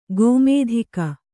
♪ gōmēdhika